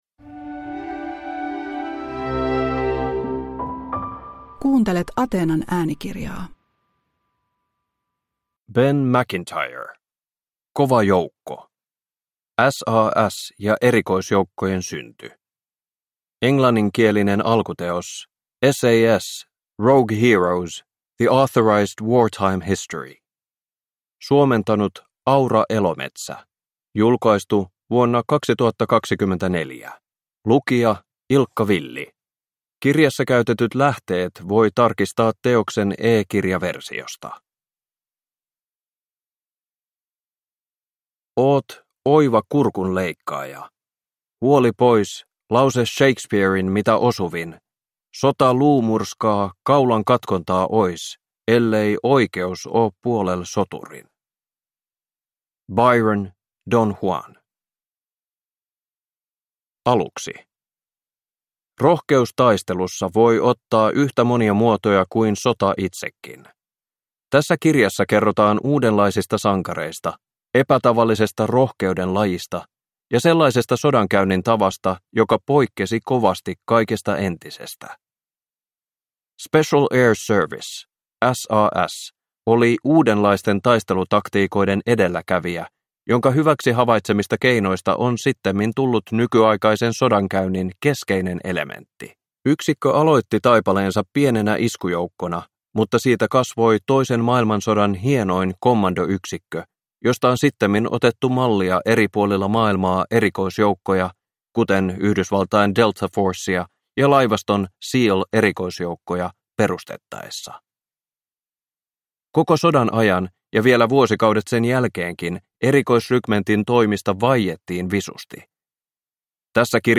Kova joukko – Ljudbok